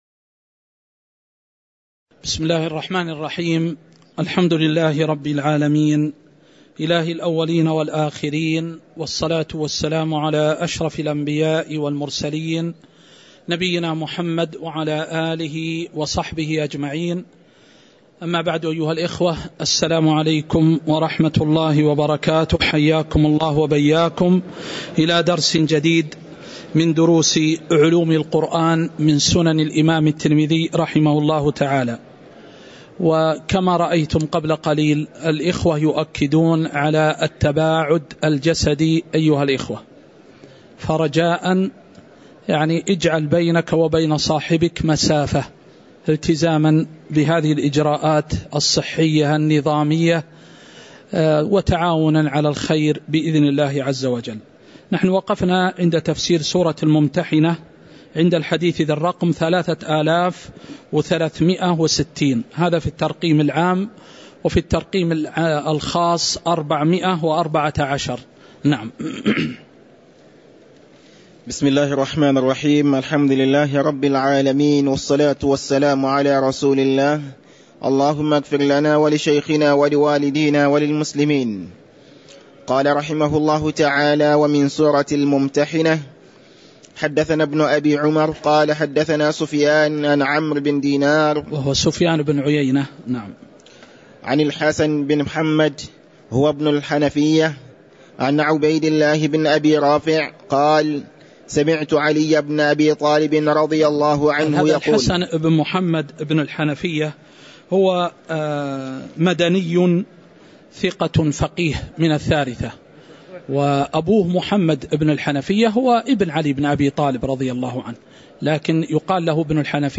تاريخ النشر ٢٠ رجب ١٤٤٣ هـ المكان: المسجد النبوي الشيخ